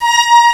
Index of /m8-backup/M8/Samples/Fairlight CMI/IIX/STRINGS1